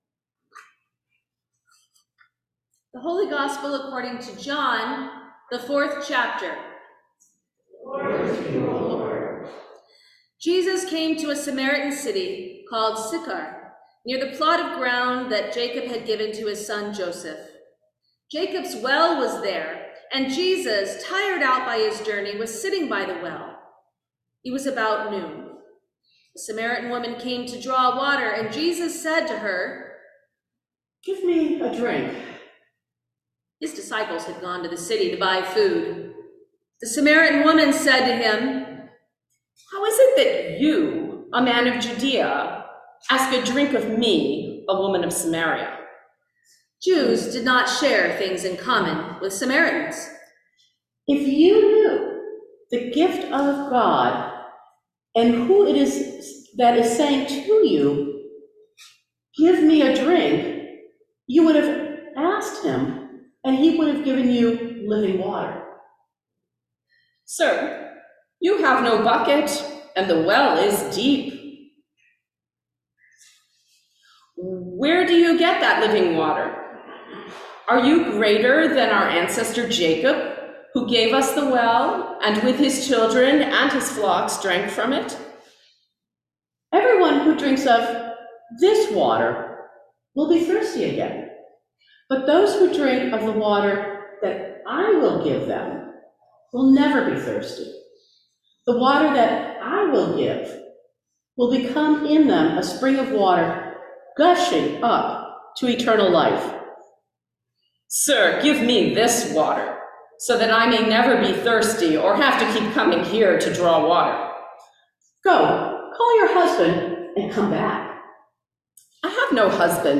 Sermon for the Third Sunday in Lent 2023